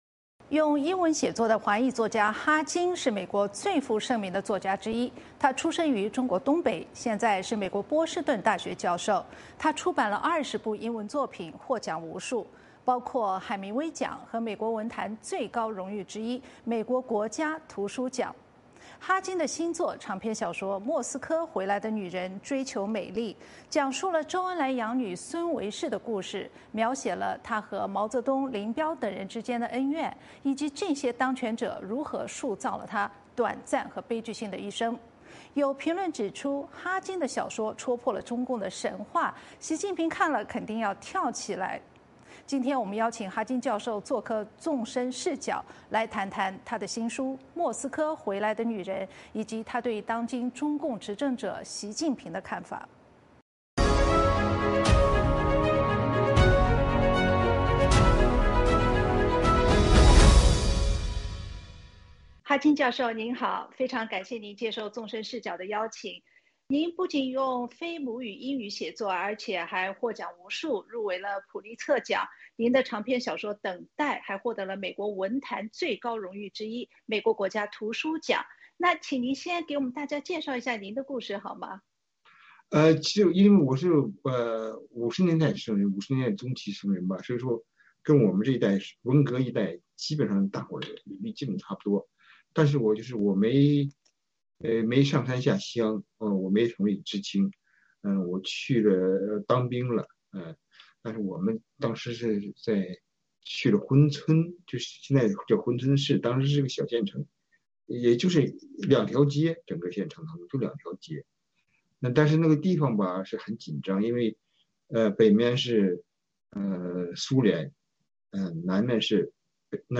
专访哈金：《莫斯科回来的女人》孙维世和毛、林、周的秘辛
哈金教授在《纵深视角》分析《莫斯科回来的女人》，以及他对当今中共领导人习近平的看法。 《纵深视角》节目进行一系列人物专访，受访者所发表的评论不代表美国之音的立场 。